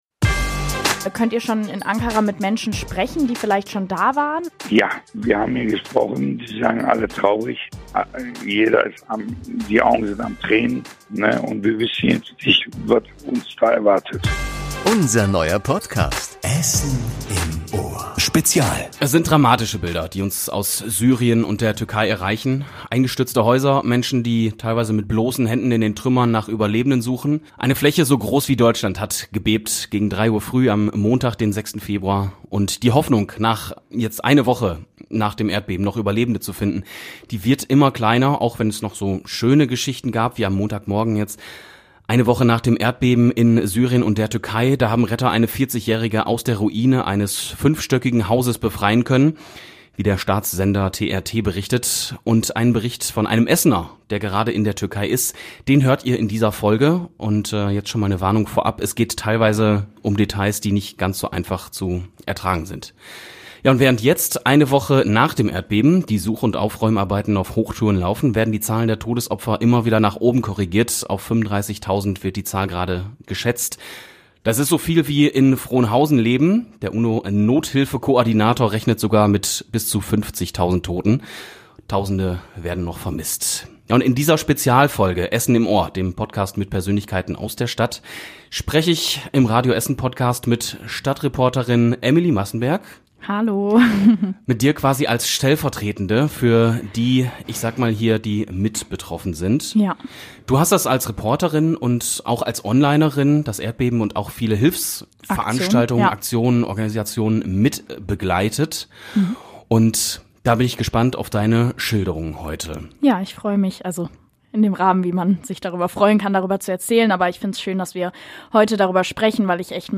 Außerdem hat sie mit Menschen gesprochen, die sich in ihrer Freizeit für die Betroffenen einsetzen. Mit dabei: Ein Helfer, der mit einem Hilfskonvoi in die Türkei gefahren ist.